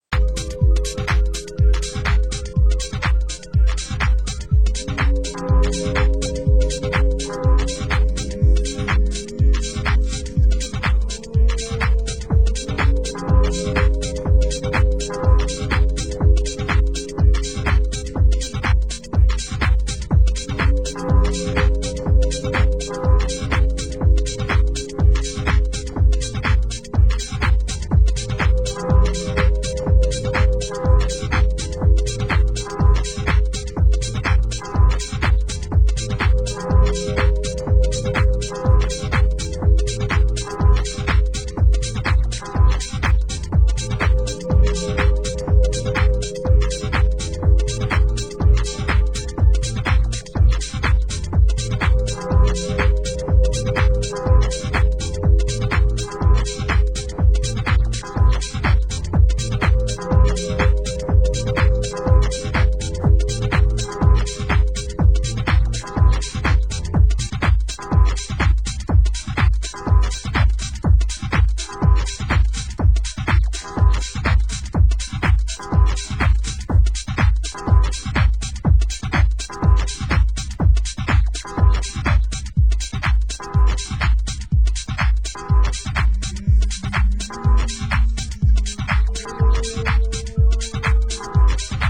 Genre: US House
INSTRUMENTAL, VOCAL